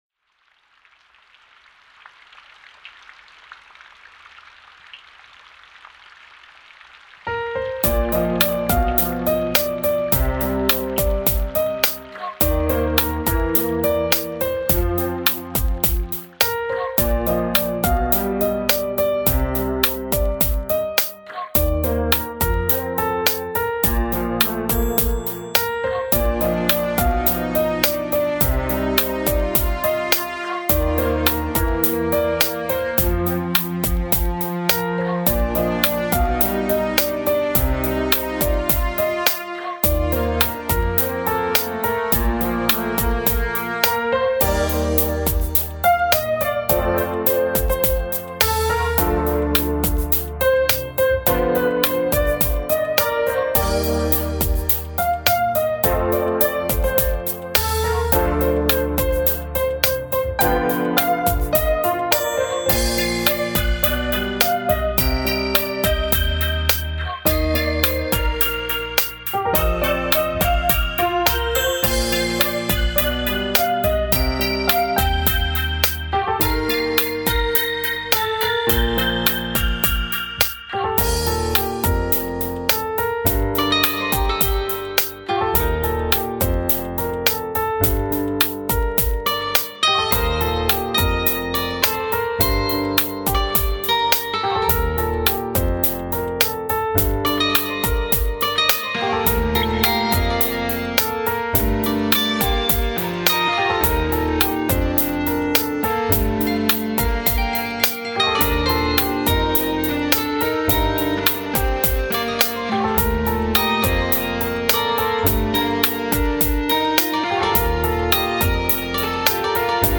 (비올 때 만든연주 곡임 ㅋㅋ)